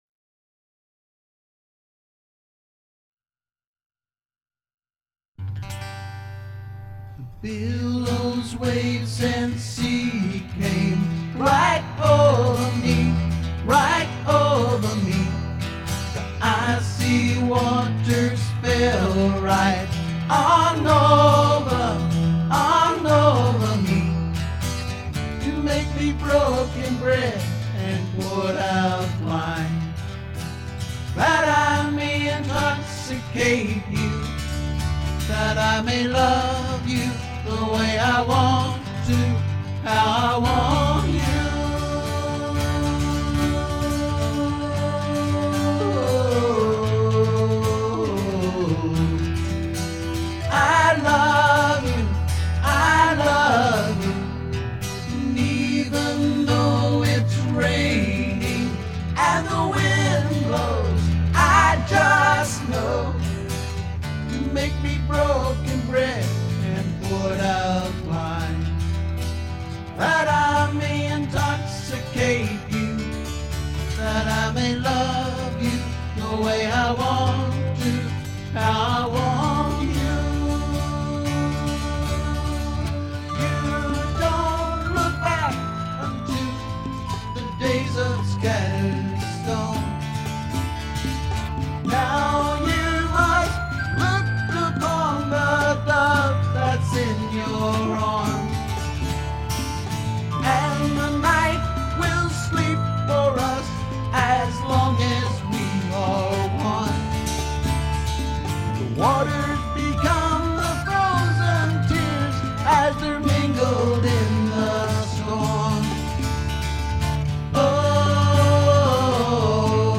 ORIGINAL ACOUSTIC SONGS
BASS